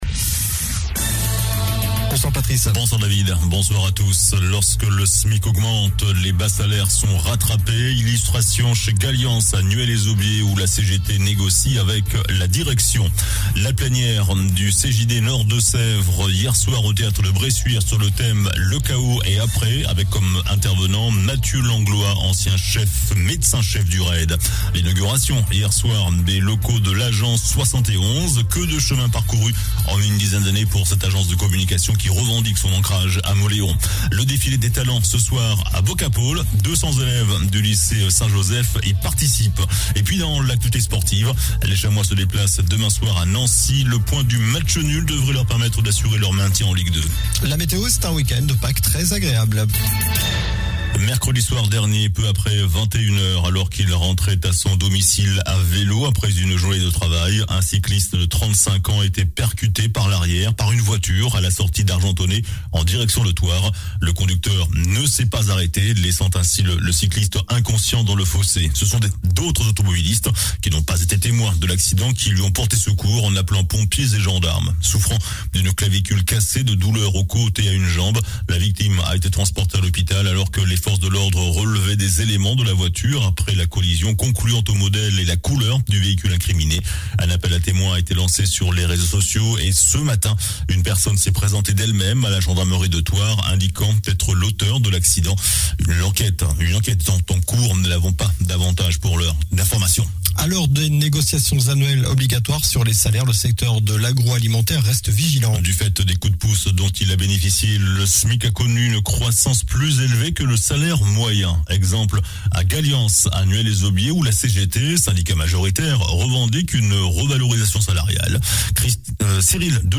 JOURNAL DU VENDREDI 15 AVRIL ( SOIR )